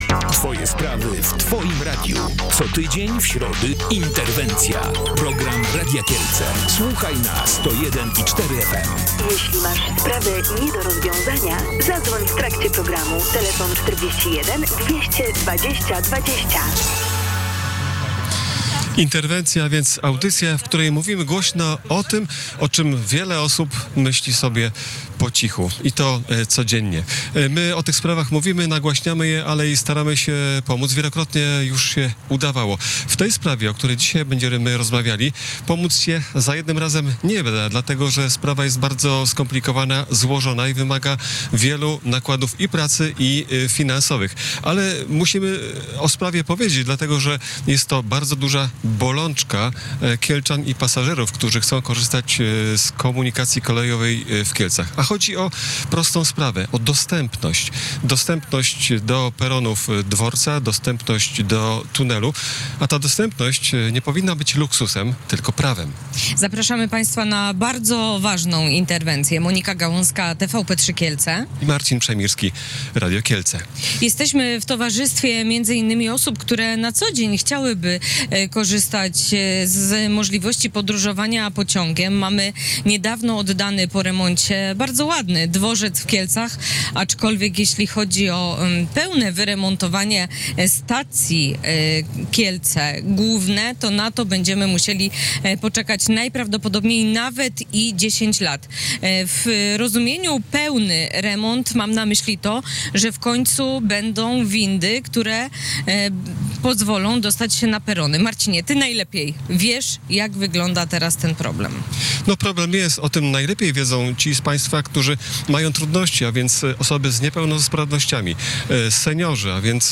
O dostępności, która nie powinna być luksusem, ale prawem rozmawialiśmy w środę (30 kwietnia) w audycji Interwencja.